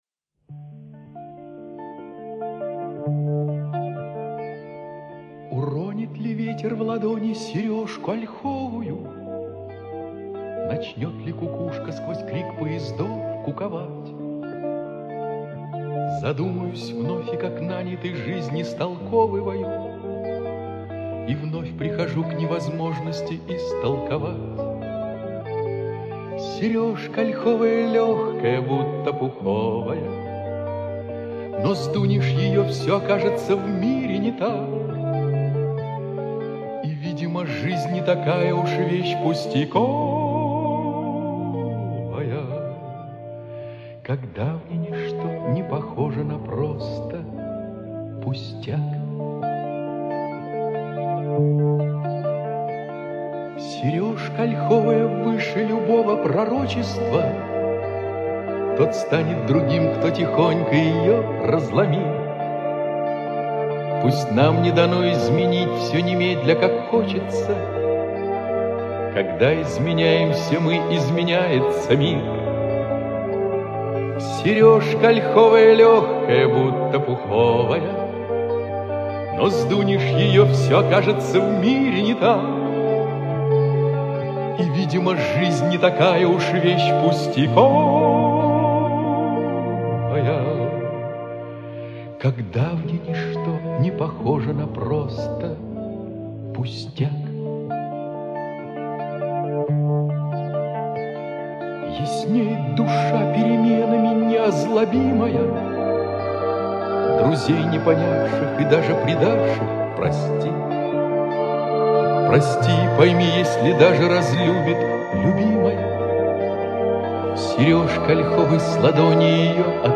И песня красивая...
Да! - Очень-очень по-весеннему!!!